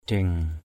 /d̪ie̞ŋ/ (cv.) ajang ajU ajâng aj;/ ajeng aj$ [A, 6-7] adieng ad`$ [A, 11]